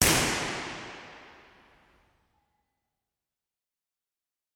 Klingt fast wie eine US Polizeisirene im Hintergrund. Aber halt sehr, sehr leise.